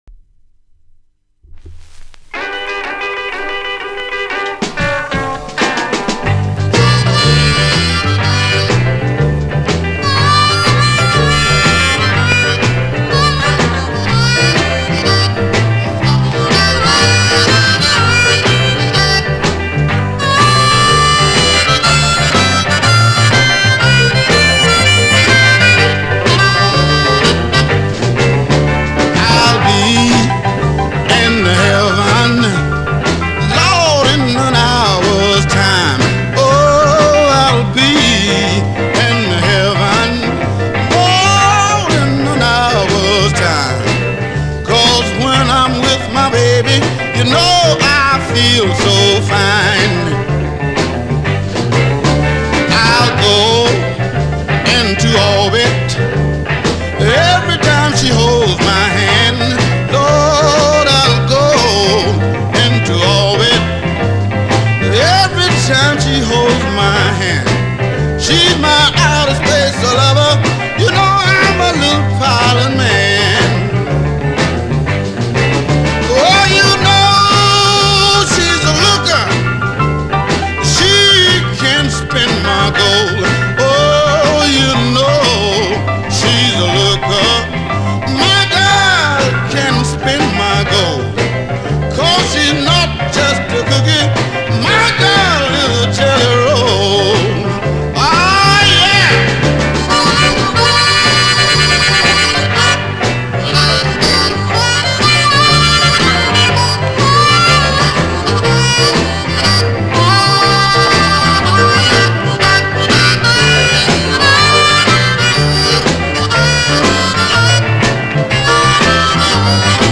was an east coast R&B singer